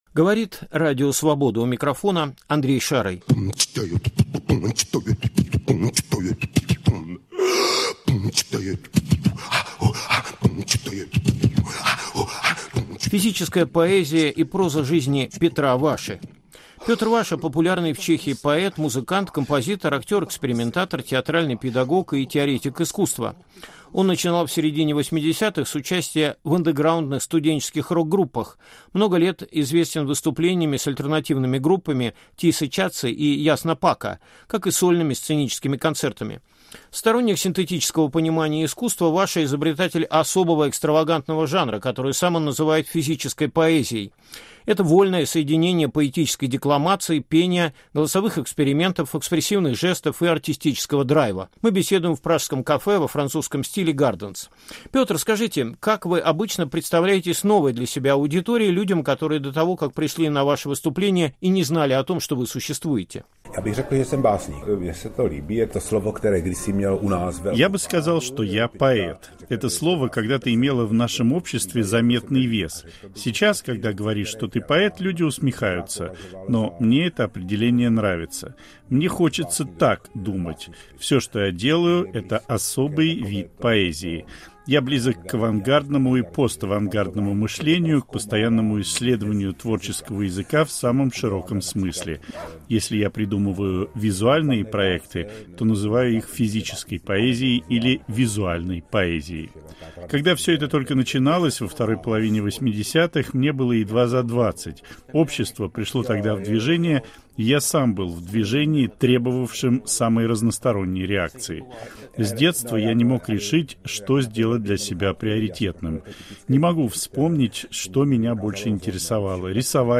Беседа с чешским художником о понимании творчества и свободы